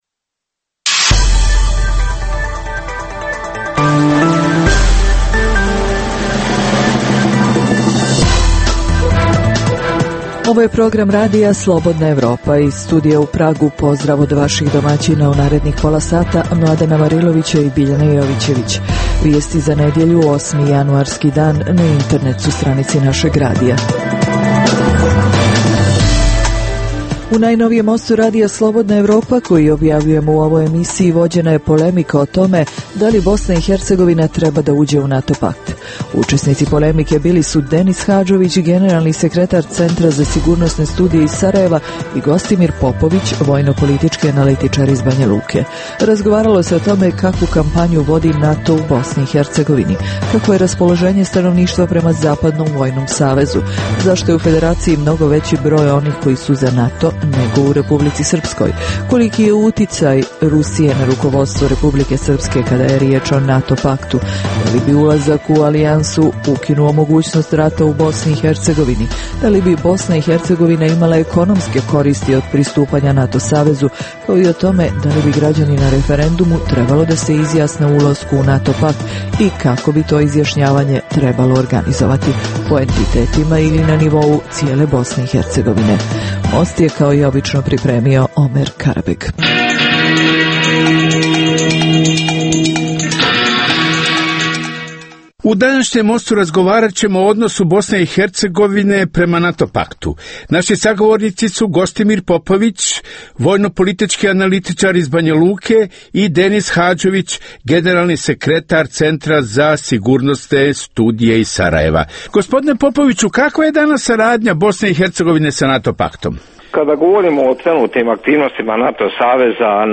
U Mostu RSE vođena je polemika o tome da li BiH teba da uđe u NATO.